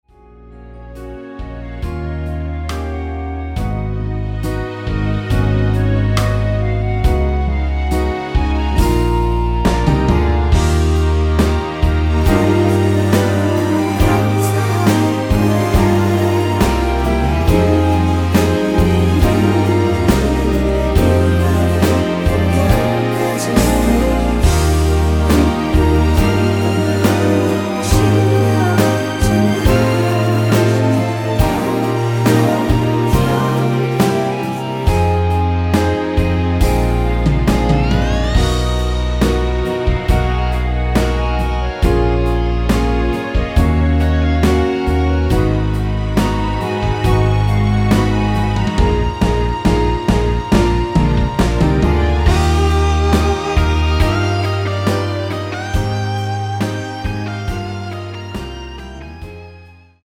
원키에서(+2)올린 멜로디와 코러스 포함된 MR입니다.(미리듣기 확인)
F#
앞부분30초, 뒷부분30초씩 편집해서 올려 드리고 있습니다.
중간에 음이 끈어지고 다시 나오는 이유는